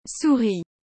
Como pronunciar corretamente souris?
Se liga na pronúncia: /suʀi/. O “r” é aquele som gutural típico do francês (meio arranhado), e o “ou” tem som de “u”.
• O “ou” soa como “u” em português.
• O “r” francês é mais forte, meio na garganta.
• O “is” no final tem som de “ri”, não “is”.
souris.mp3